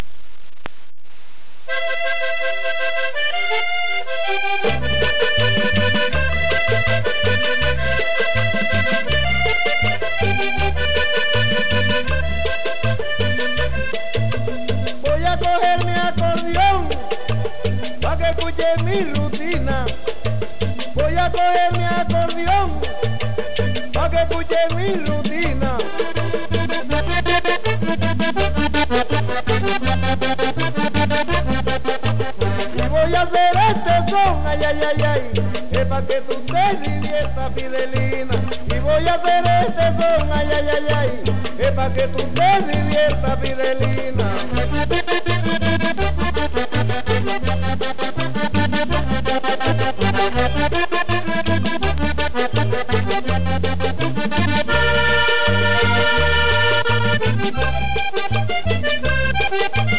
Son vallenato